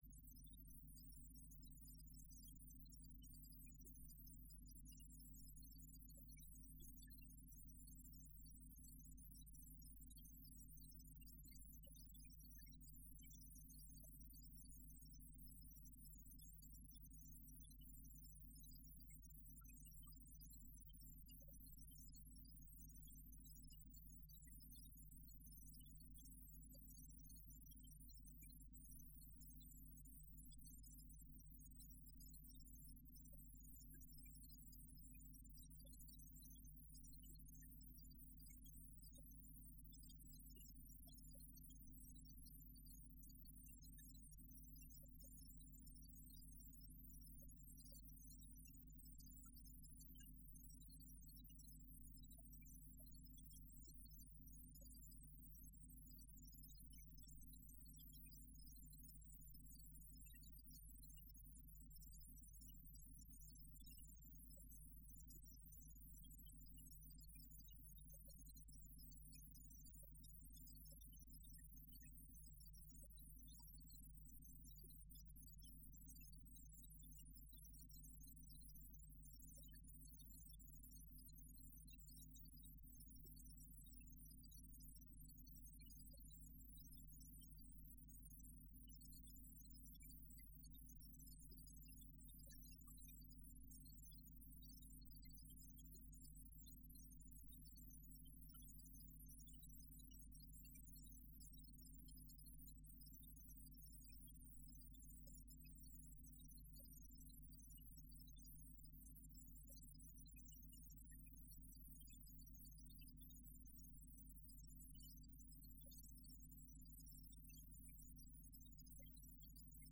הנחיות מדיטציה - בוקר
דף הבית › ספריה › ספרית הקלטות הנחיות מדיטציה - בוקר הנחיות מדיטציה - בוקר 0:00 0:00 סוג ההקלטה: סוג ההקלטה: שיחת הנחיות למדיטציה שפת ההקלטה: שפת ההקלטה: עברית